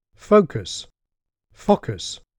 Here, for comparison, is native focus followed by a problematic ‘fockus’:
focus_fockus.mp3